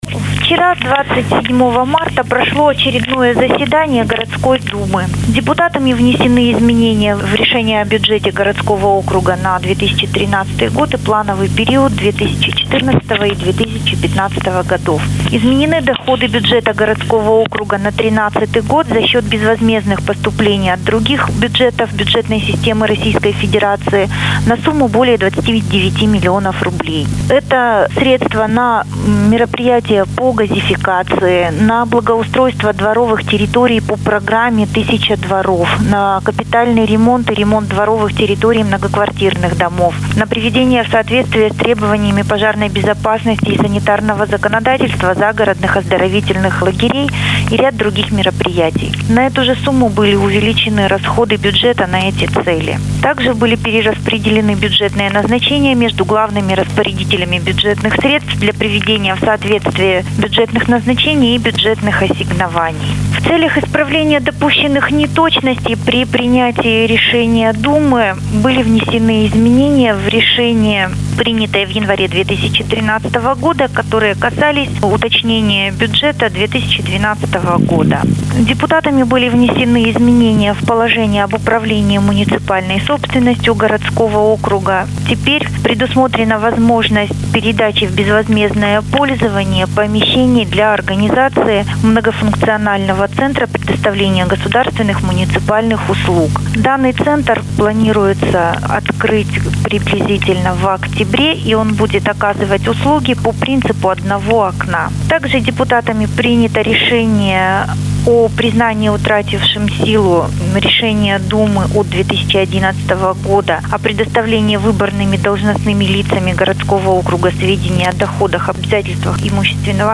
«запись с телефона»